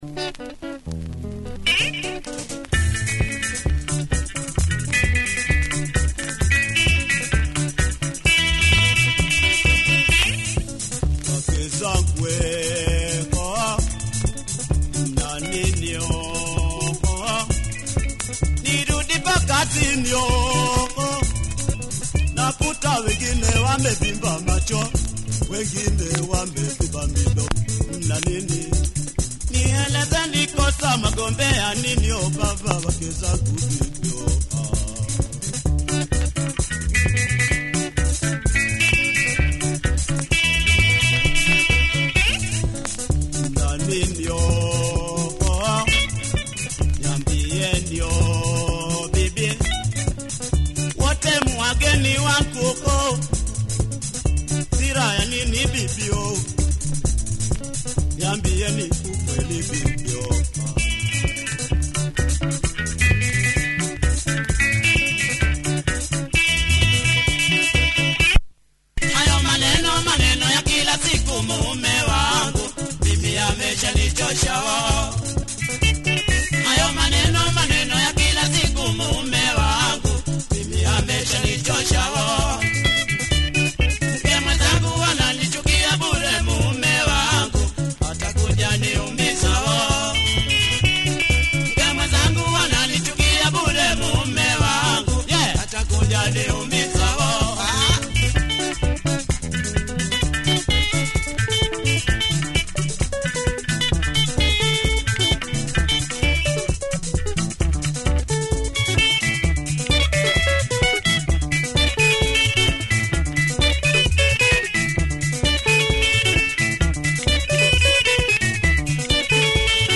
Quality Kamba benga mover here